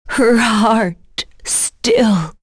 Seria-Vox_Dead.wav